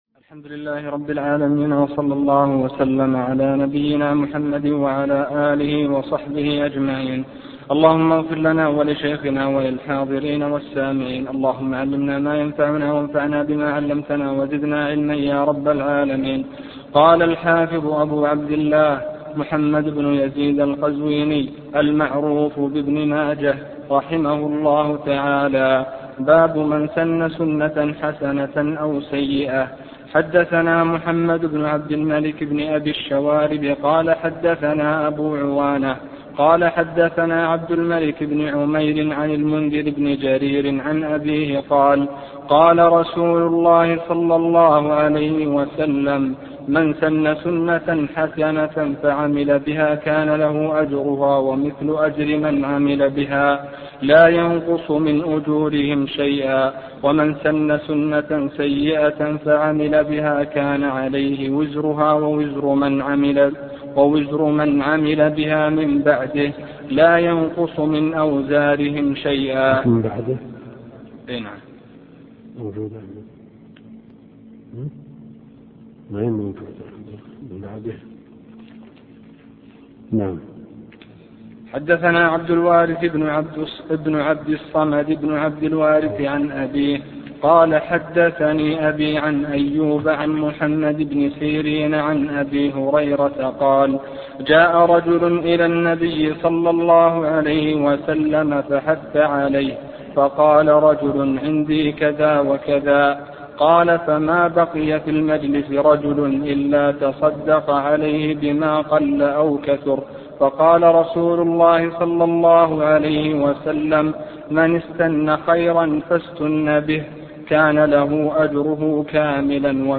الدرس (20) شرح سنن ابن ماجه - الدكتور عبد الكريم الخضير